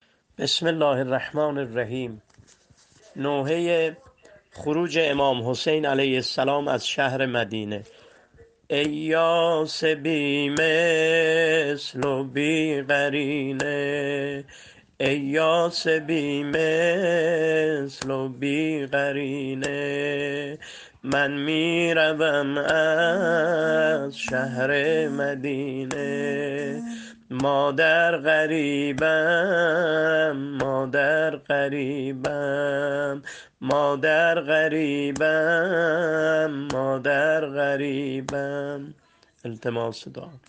متن شعر نوحه خروج امام حسین علیه السلام از مدینه -(ای یاس بی مثل وبی قرینه)